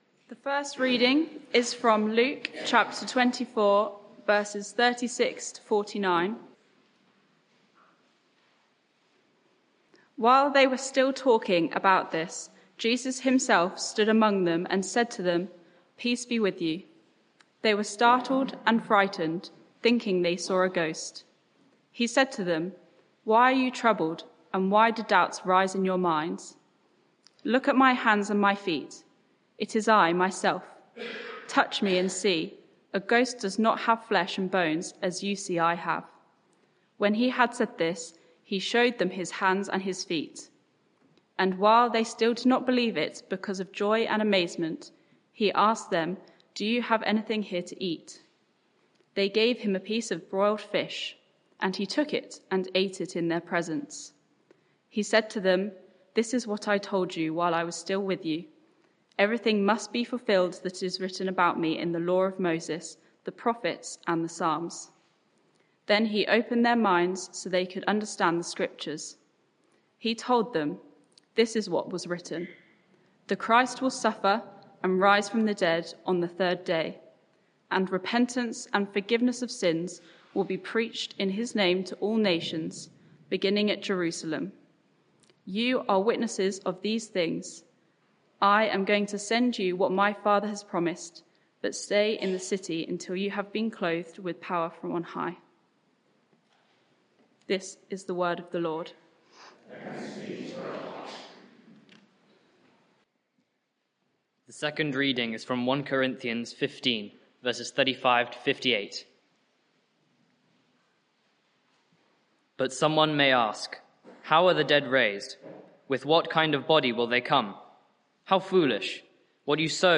Sermon (audio)